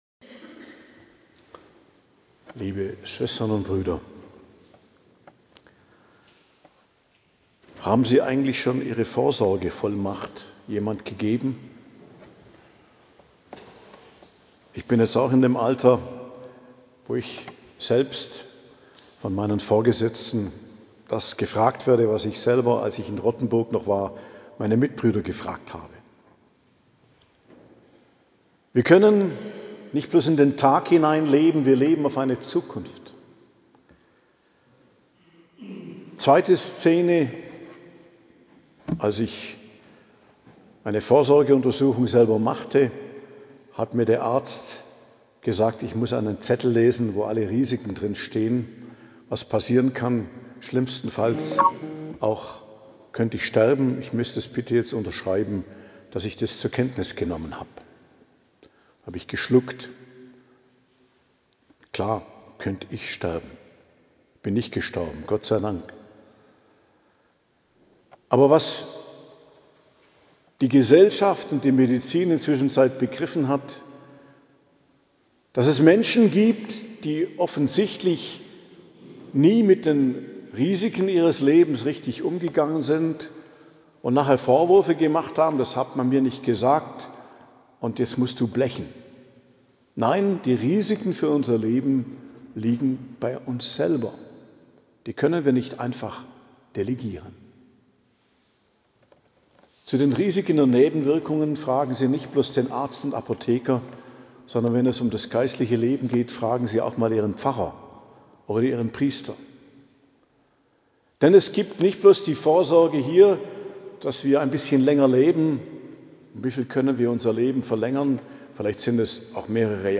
Predigt zum 26. Sonntag i.J., 28.09.2025 ~ Geistliches Zentrum Kloster Heiligkreuztal Podcast